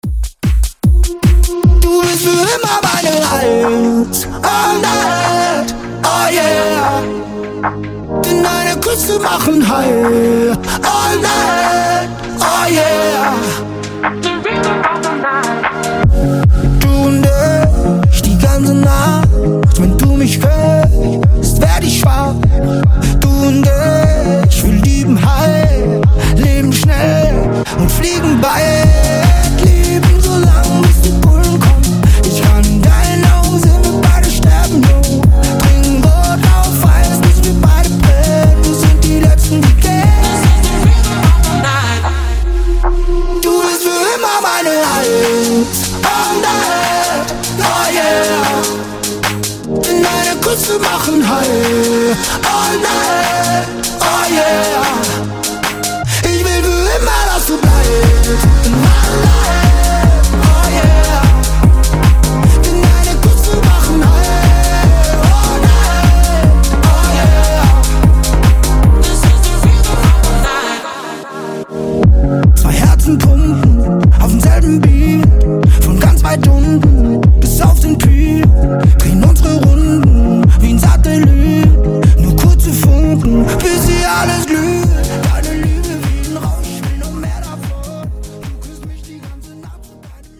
Genre: 2000's
Clean BPM: 108 Time